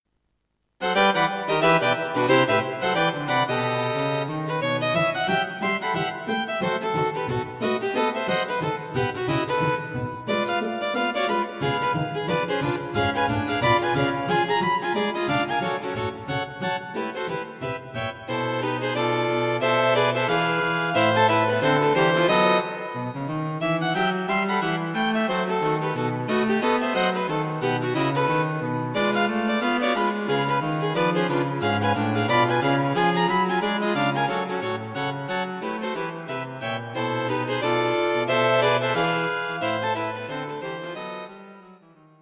String Quartet for Concert performance